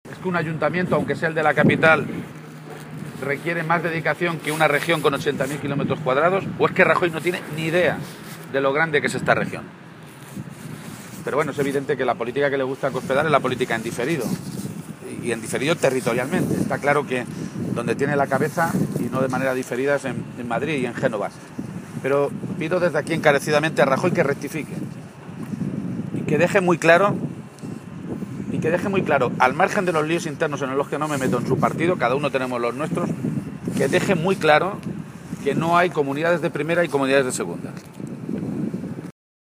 García-Page se pronunciaba de esta manera esta mañana, en Cuenca, en una comparecencia ante los medios de comunicación, en la que aseguraba que lo más triste de este tipo de cosas (derrumbes de techos de hospitales y despidos como ejemplo del desmantelamiento de los servicios públicos esenciales) es que han ocurrido no solo después de que Cospedal prometiera que no iba a traspasar las líneas rojas de sus recortes en la Sanidad o la Educación, sino “después de que ver cómo vamos a terminar la legislatura con 6.000 millones de euros más de deuda pública. Es decir, que con Cospedal hemos acumulado más deuda pública en tres años que en los treinta anteriores de la historia” de Castilla-La Mancha.